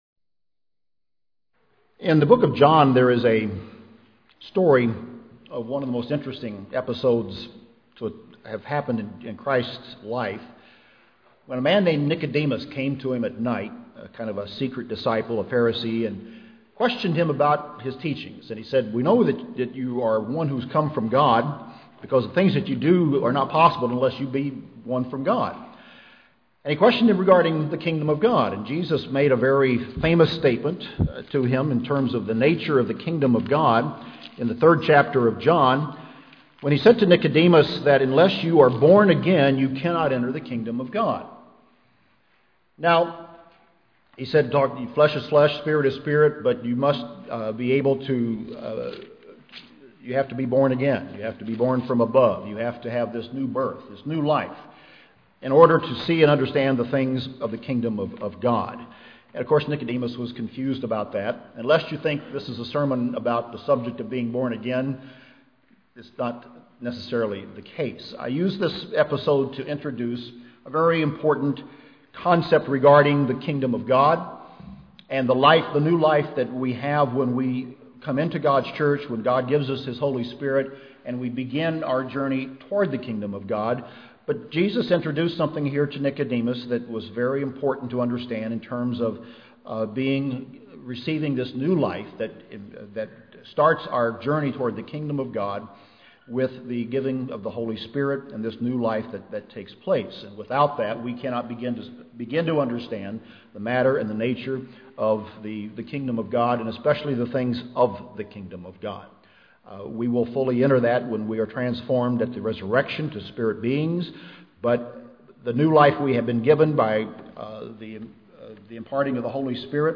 The Sermon on the Mount, Part 1 | United Church of God